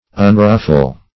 Search Result for " unruffle" : The Collaborative International Dictionary of English v.0.48: Unruffle \Un*ruf"fle\, v. i. [1st pref. un- + ruffle.] To cease from being ruffled or agitated.